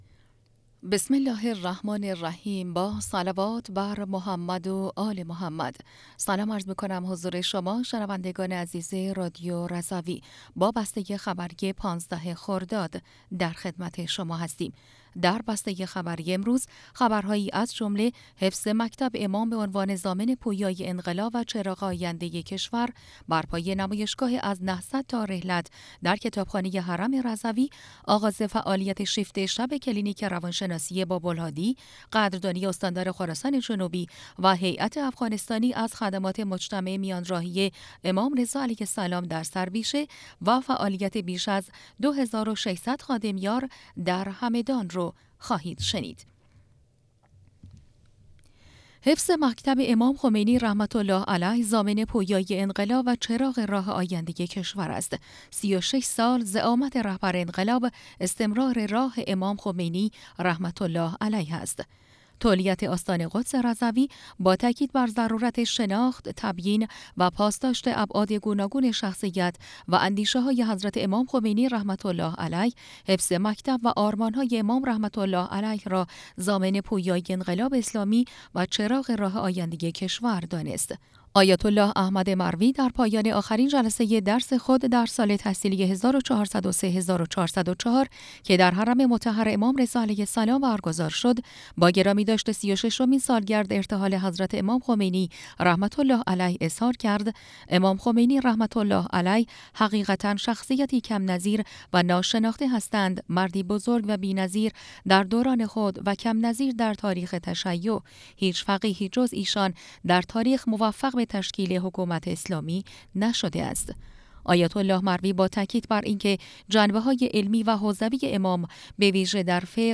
بسته خبری ۱۵ خرداد ۱۴۰۴ رادیو رضوی/